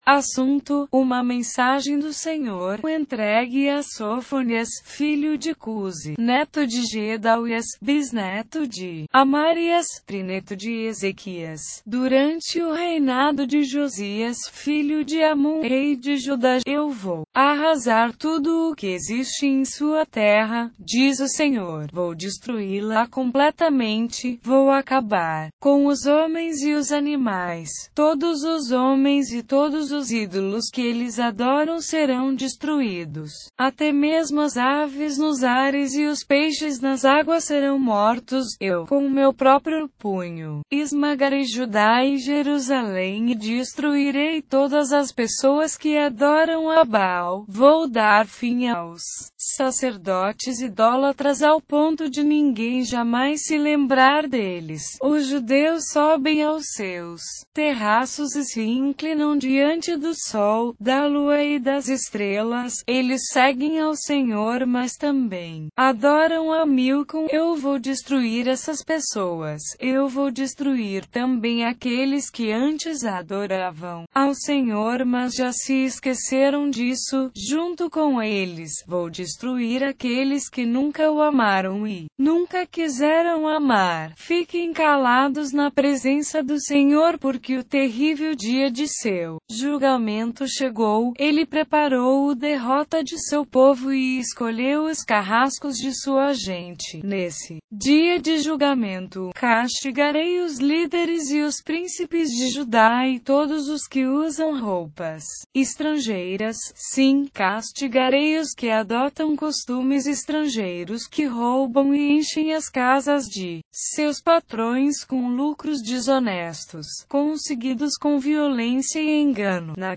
Leitura na versão Bíblia Viva - Português